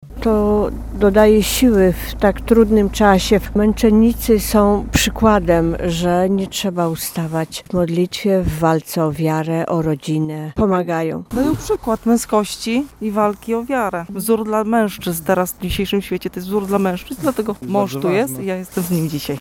Mówią wierni